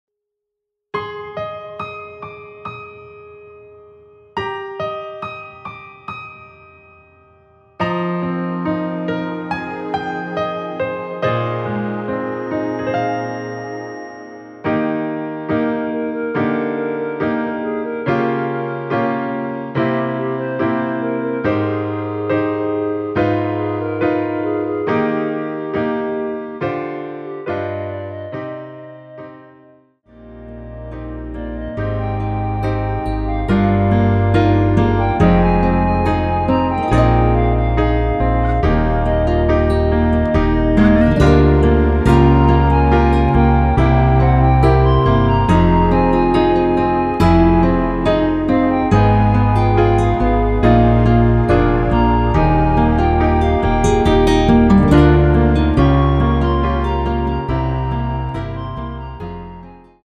원키에서(+5)올린 멜로디 포함된 MR입니다.
Eb
앞부분30초, 뒷부분30초씩 편집해서 올려 드리고 있습니다.
중간에 음이 끈어지고 다시 나오는 이유는